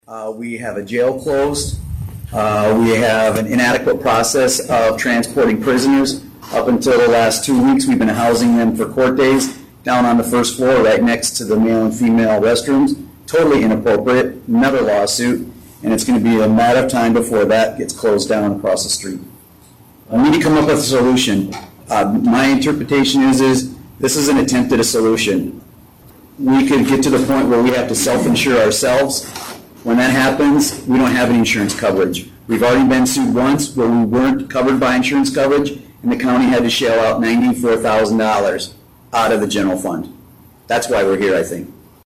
Walworth County State’s Attorney James Hare stated the reason for the meeting.